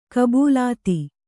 ♪ kabūlāti